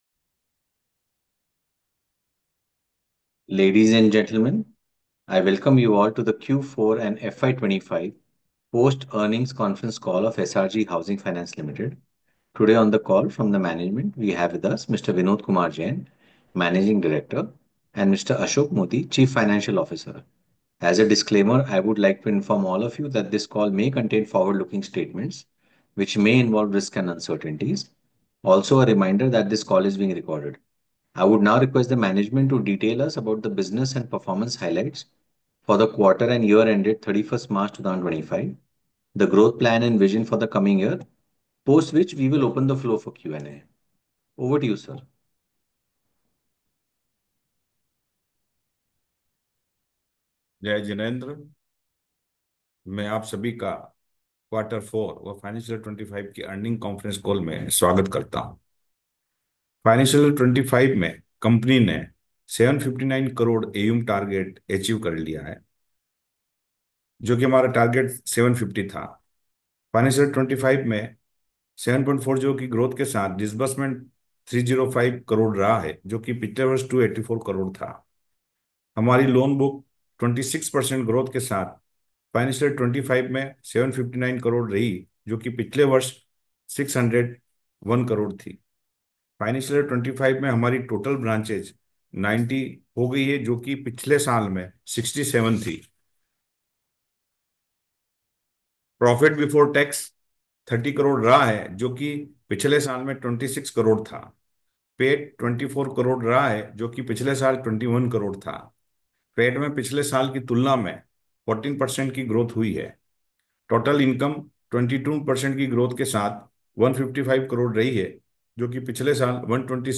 Audio Recording of Q4FY25 Earnings Conference Call (Date - 31.03.2025)